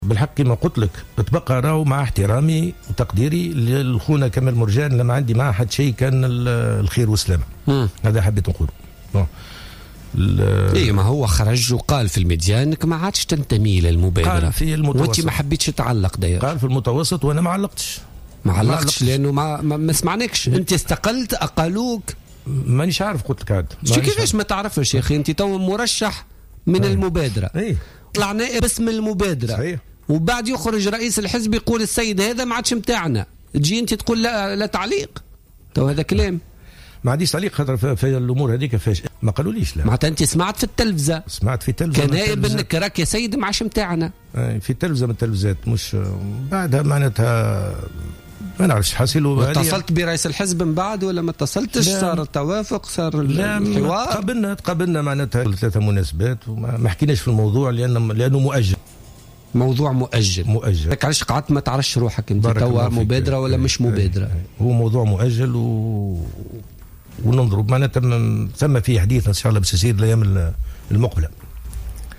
قال أحمد السعيدي النائب في مجلس نواب الشعب عن حزب المبادرة وضيف برنامج بوليتكا لليوم الخميس 03 مارس 2016 إنه لا يعلم هل أنه مازال ينتمي إلى حزب المبادرة أو أنه قد تم التخلي عنه.